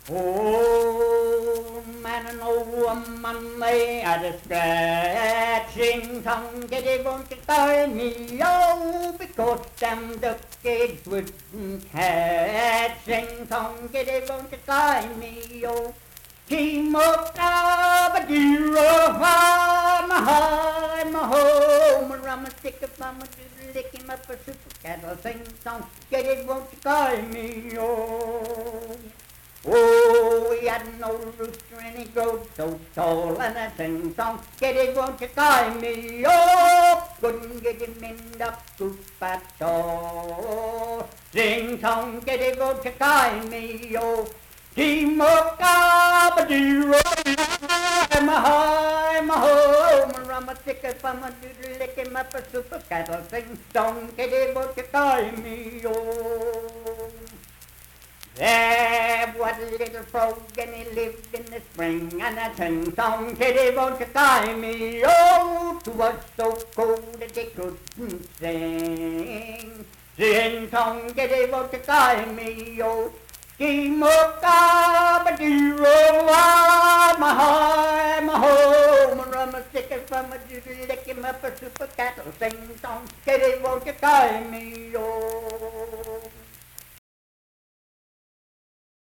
Unaccompanied vocal music performance
Minstrel, Blackface, and African-American Songs
Voice (sung)